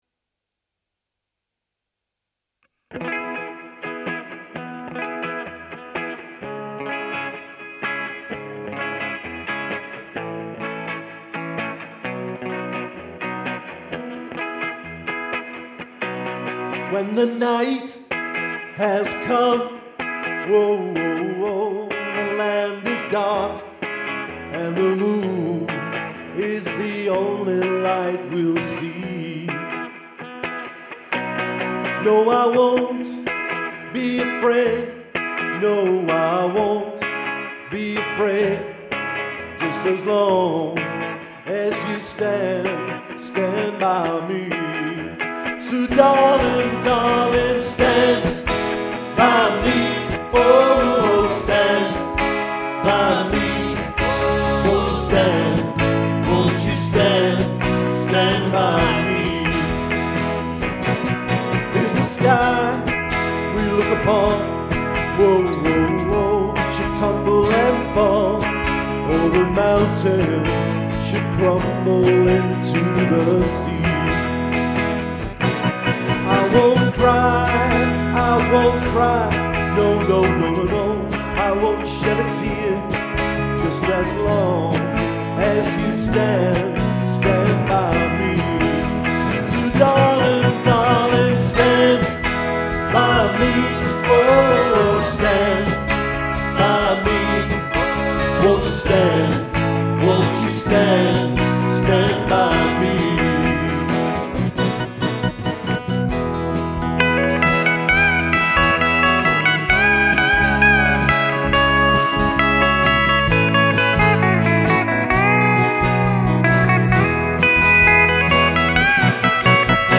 at his gravelly best ...